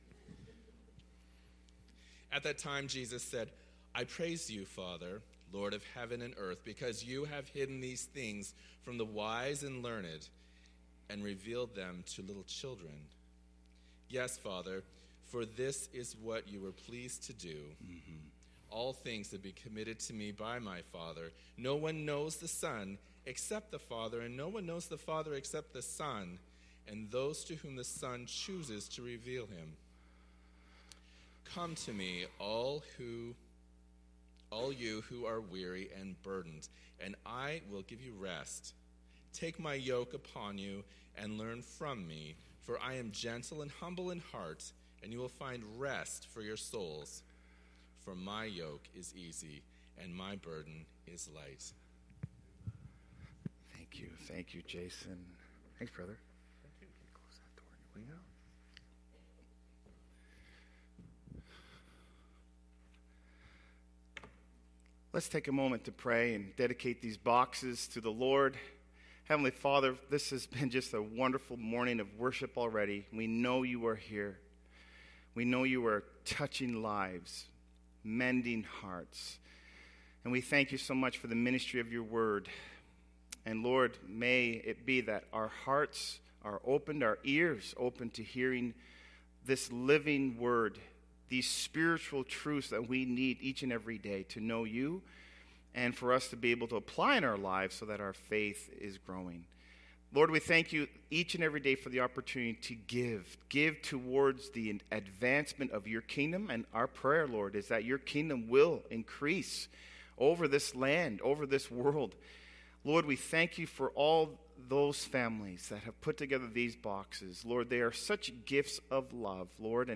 Audio Sermons - Campbellford Baptist Church Inc.